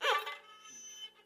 二胡长篇笔记 " 长垣快环
描述：在二胡上演奏的中长音符，是一种双弦的中国小提琴。
标签： 拉二胡 弦乐 小提琴
声道立体声